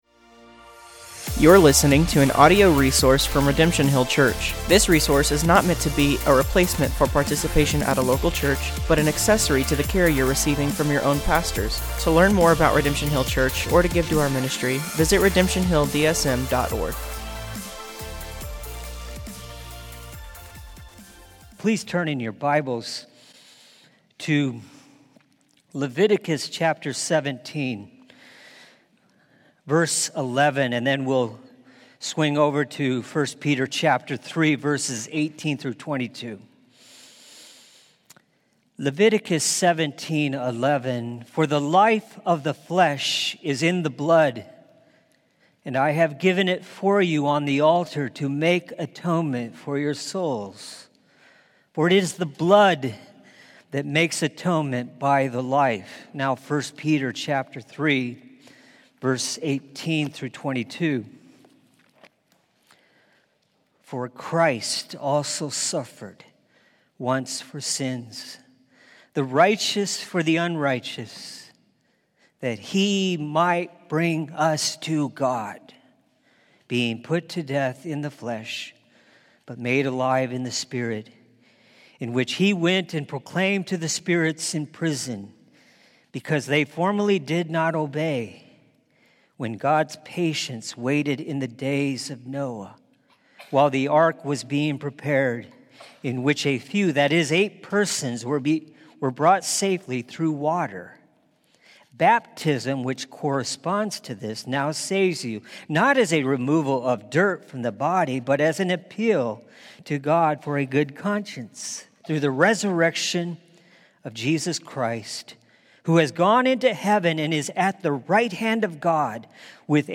Sermons | Redemption Hill Church Des Moines, IA